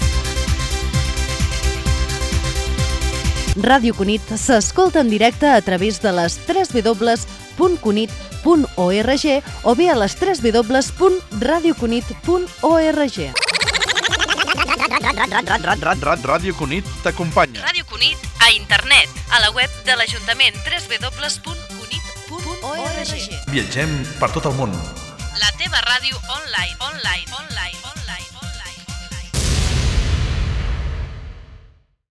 4f2239b78a233e4e7d985003a14fbc8fe2beb022.mp3 Títol Ràdio Cunit Emissora Ràdio Cunit Titularitat Pública municipal Descripció Identificació i pàgina web on es pot escoltar l'emissora.